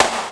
Snare Drum 67-07.wav